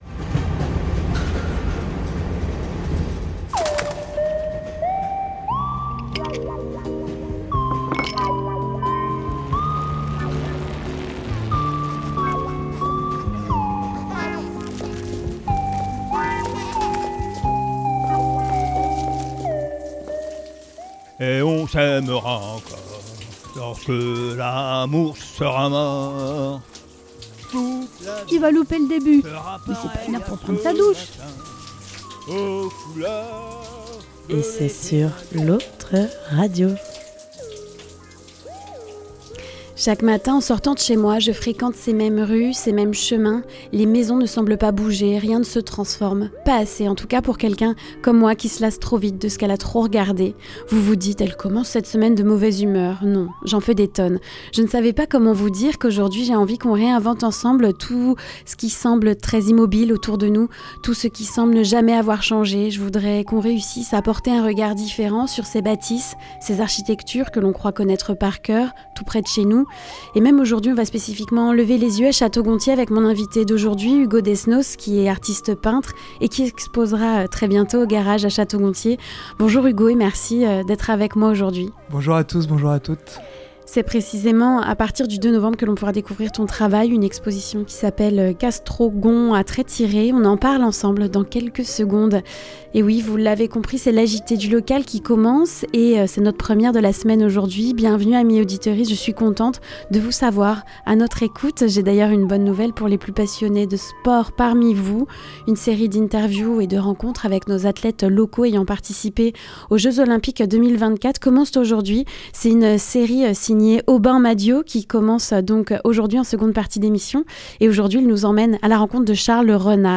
Revue de presse du Haut Anjou.